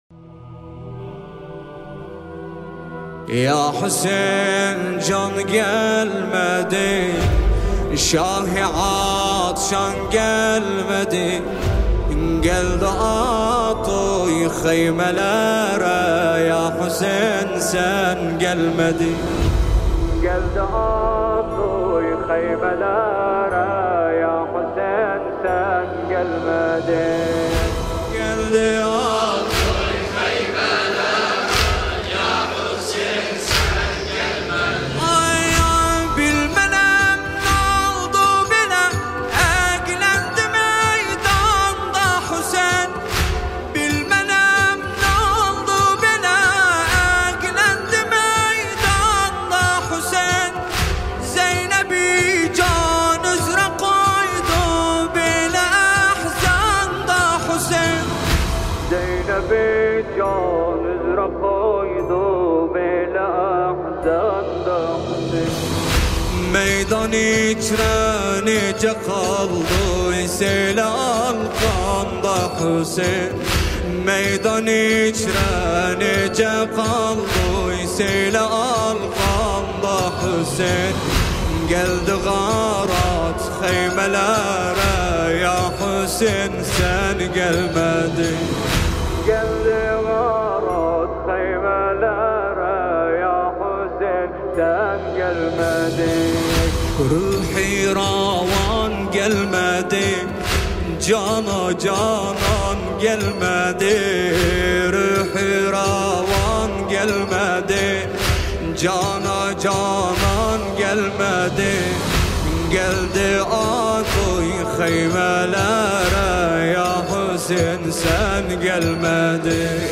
نوحه ترکی عراقی